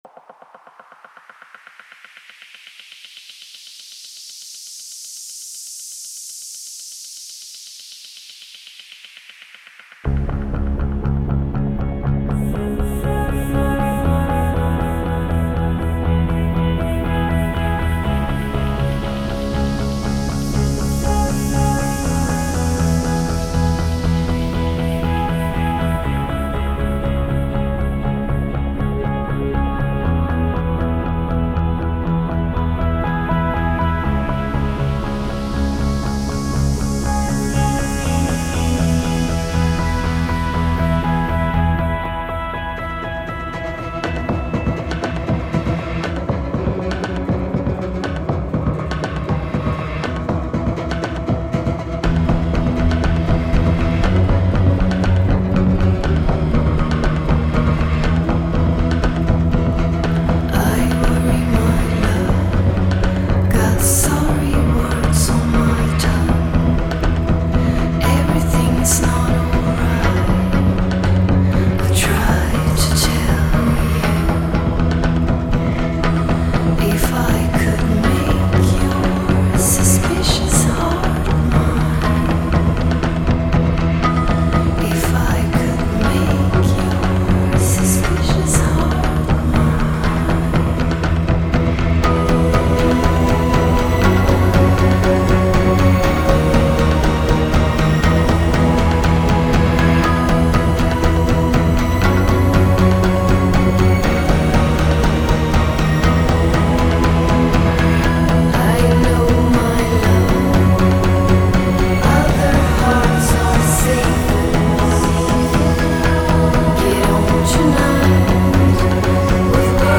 superb dreampop
both the longest and heaviest they have on offer